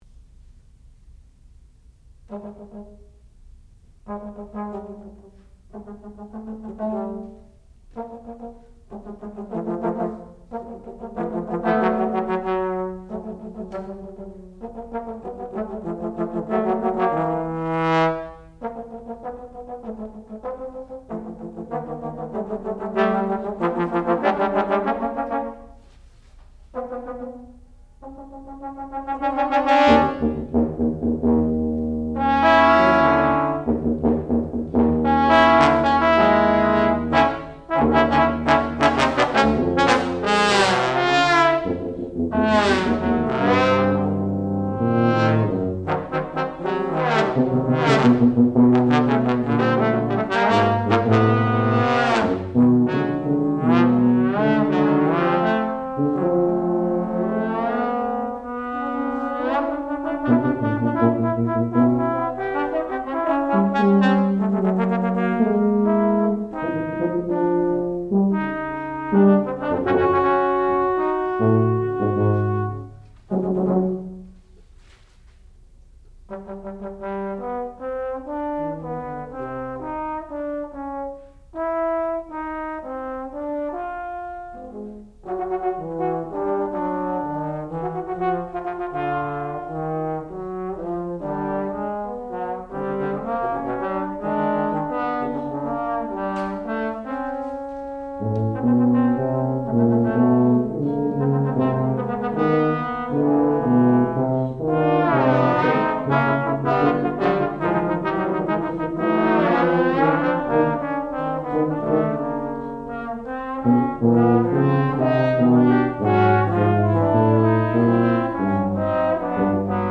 sound recording-musical
classical music
Qualifying Recital